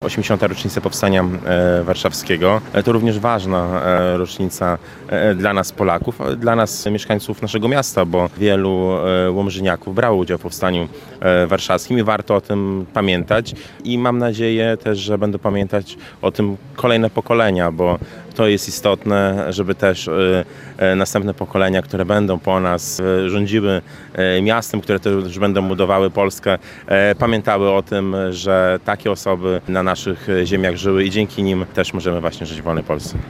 Naszym zadaniem jako dorosłych jest przekazywanie historii i dawanie przykładu młodym pokoleniom- mówił prezydent Łomży Mariusz Chrzanowski: